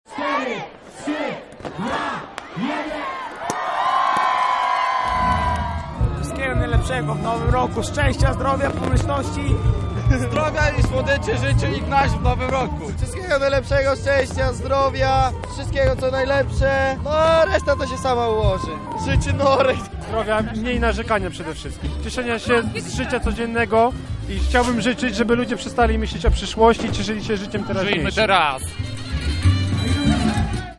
Cztery sceny, różne klimaty muzyczne i wspólne odliczanie ostatnich sekund do północy – tak mieszkańcy Lublina powitali Nowy Rok.
Nowa, nietypowa odsłona sylwestra miejskiego zgromadziła tysiące lublinian na wspólnej zabawie.